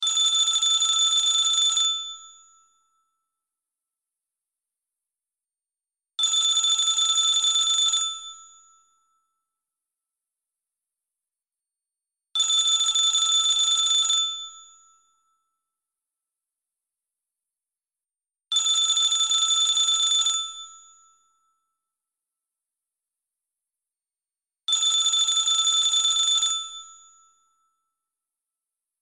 Klassisk Telefon, Android, Klassisk